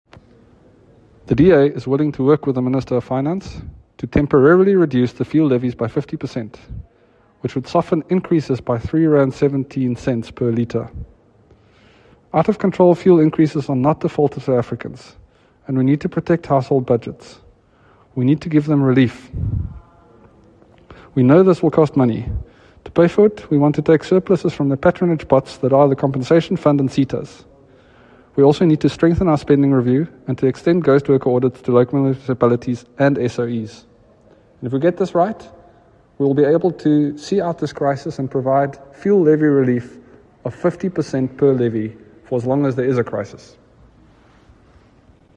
Afrikaans soundbites by Dr Mark Burke MP.